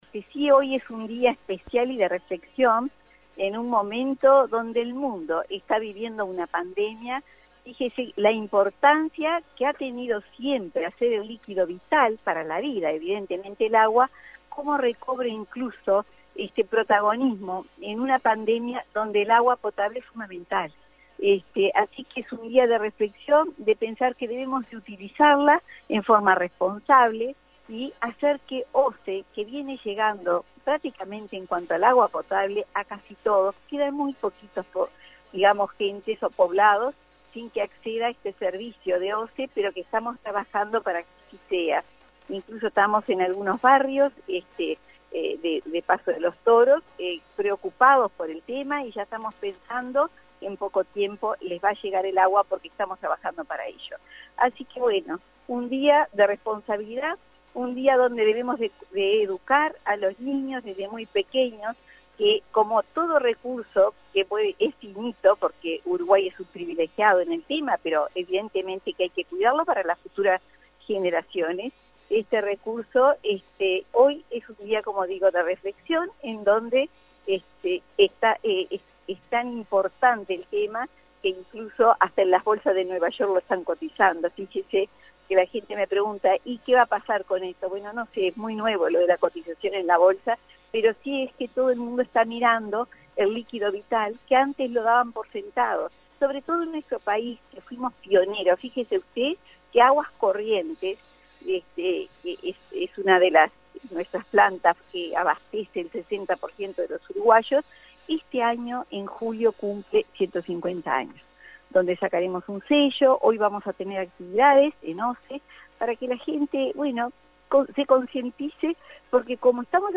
La Vicepresidenta de OSE, Susana Montaner, se expresó al respecto a AM 1110 en la jornada de hoy, dijo que el agua potable tomó protagonismo en la pandemia, ya que es fundamental, «es un día de reflexión, de pensar que debemos utilizarla en forma responsable» añadió.